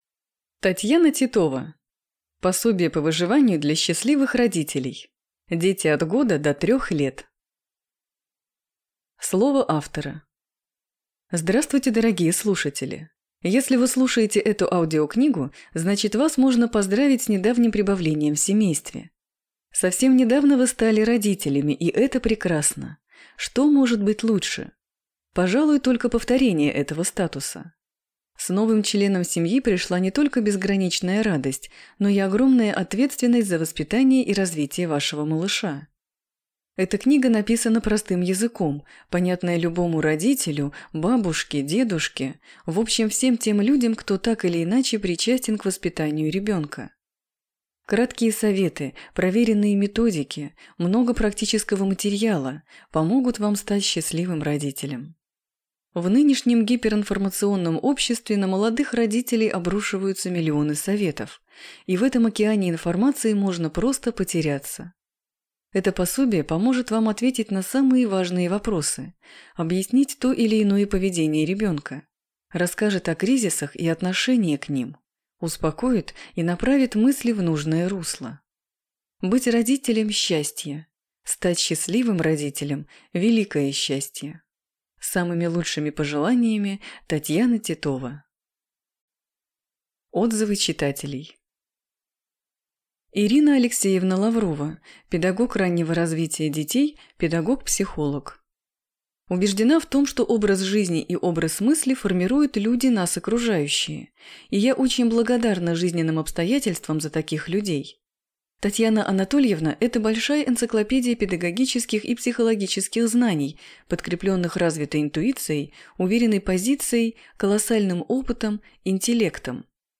Аудиокнига Пособие по выживанию для счастливых родителей. Дети от 1 до 3 лет | Библиотека аудиокниг